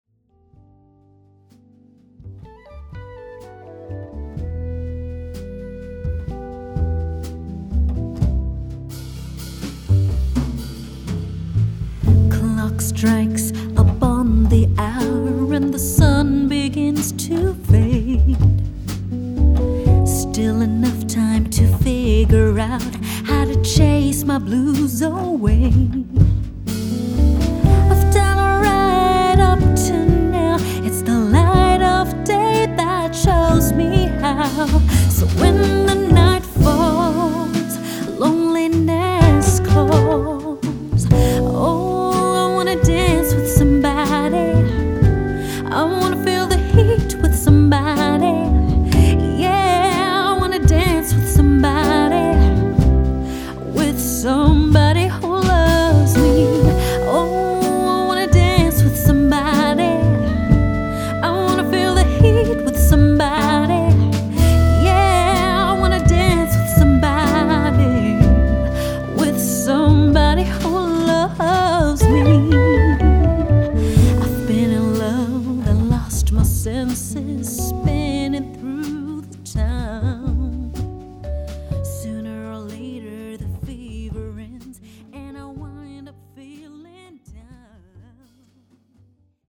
Vip Lounge Music